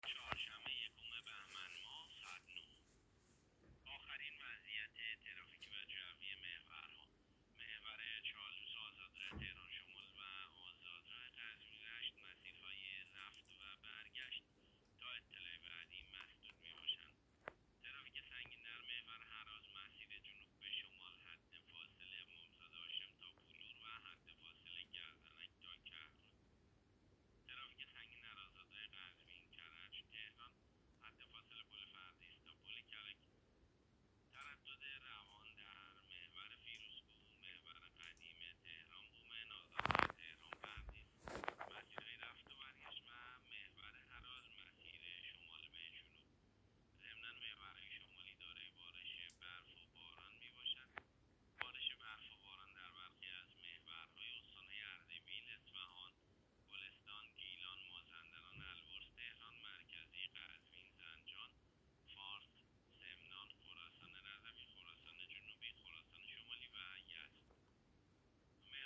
گزارش رادیو اینترنتی از آخرین وضعیت ترافیکی جاده‌ها ساعت ۹ اول بهمن؛